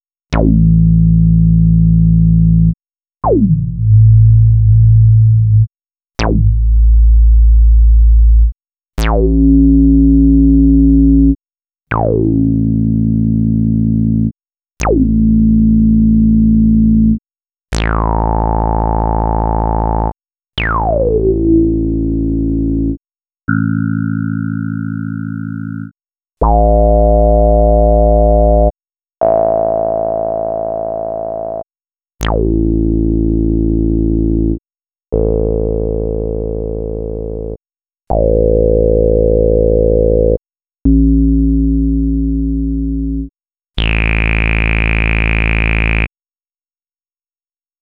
11_AcidBass.wav